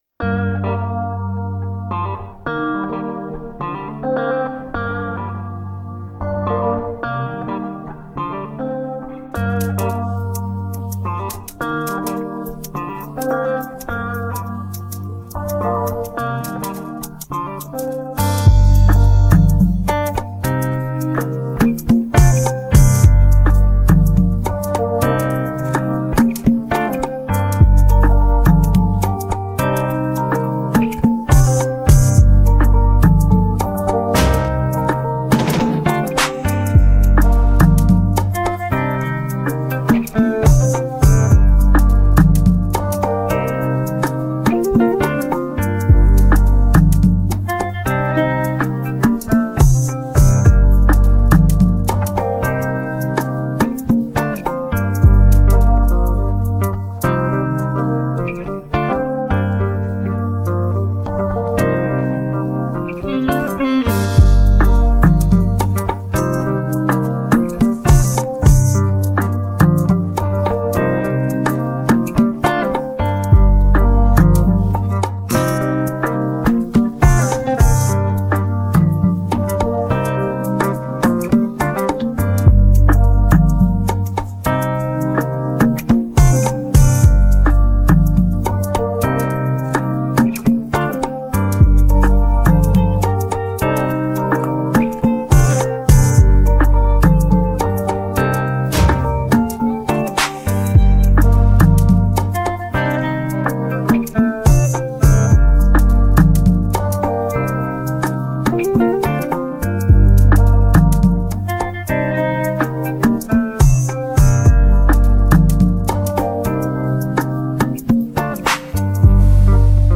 Afro pop Afrobeats